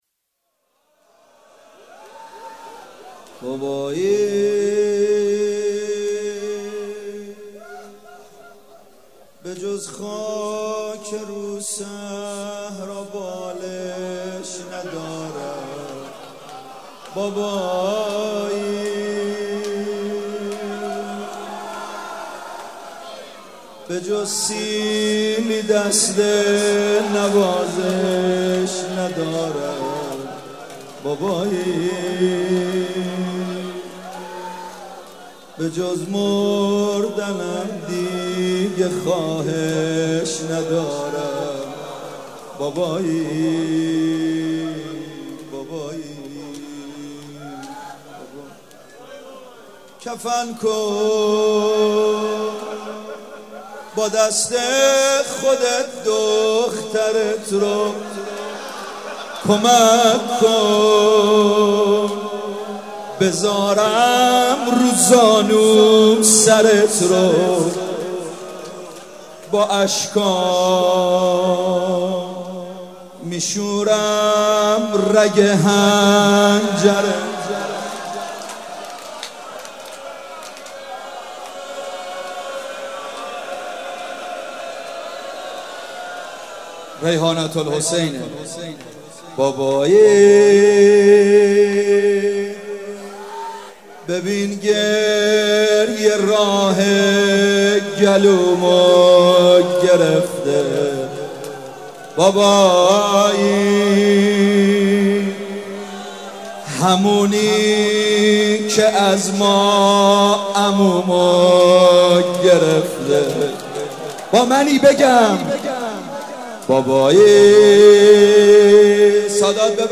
مداحی شب سوم صفر/هیئت ریحانه الحسین(س)
روضه